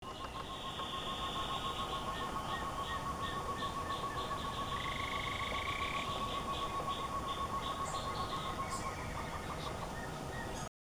Short-tailed Antthrush (Chamaeza campanisona)
Life Stage: Adult
Location or protected area: Parque Nacional Iguazú
Condition: Wild
Certainty: Recorded vocal